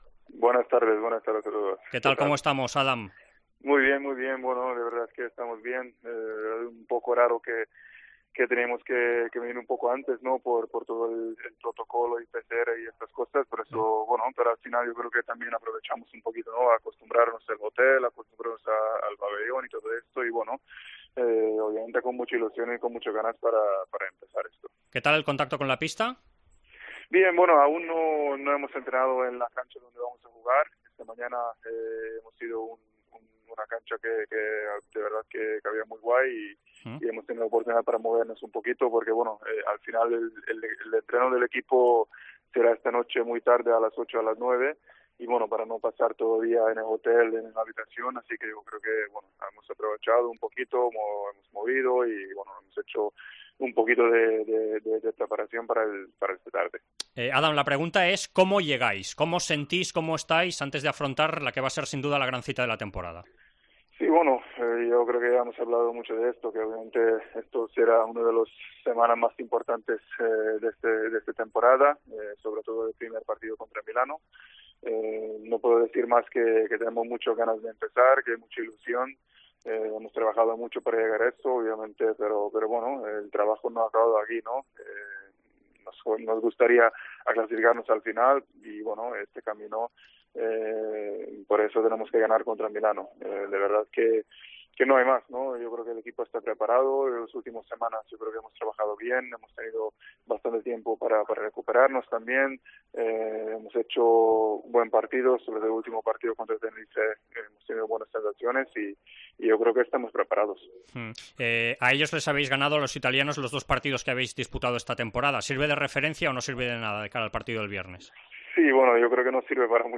El jugador del FC Barcelona Adam Hanga ha atendido a Esports COPE a pocas horas del debut en la Final Four de Colonia contra Armani Milán "somos un equipo hecho para llegar a esta Final Four, pero ha costado mucho llegar. La experiencia siempre es un factor, pero es un partido.